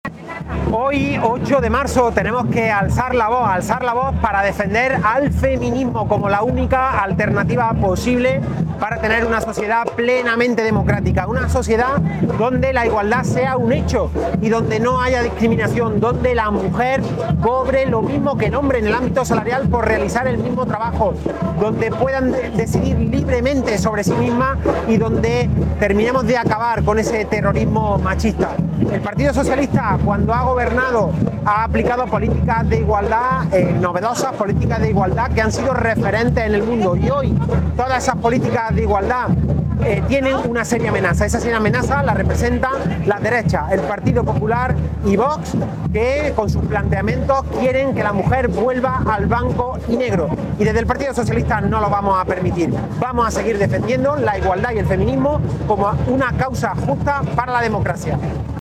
durante su participación en la manifestación del 8 de marzo que ha recorrido las calles de la capital